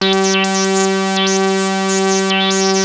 Index of /90_sSampleCDs/Hollywood Edge - Giorgio Moroder Rare Synthesizer Collection/Partition A/ARP 2600 7